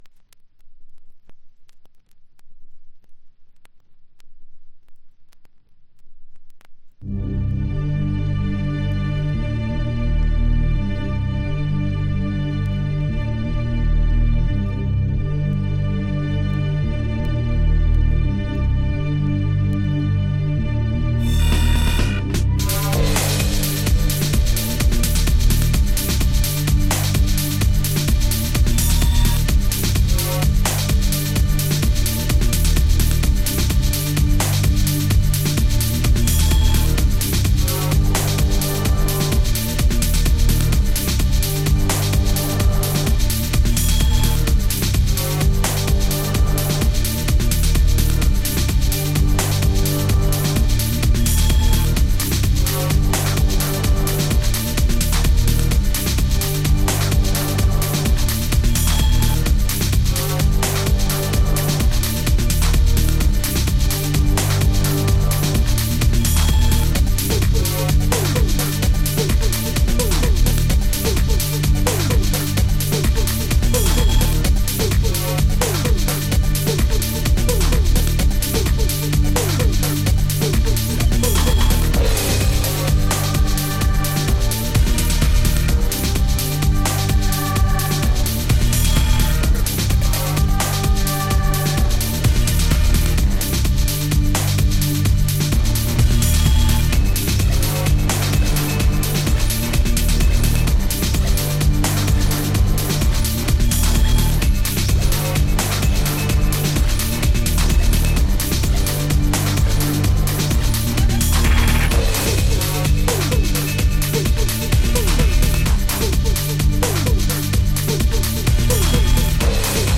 出音はキラキラ感は無くドープな音作りなのですが、曲の構成や華やかな仕掛けが散りばめられた一枚になっています！
House / Techno